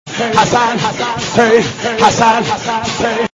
دانلود سبک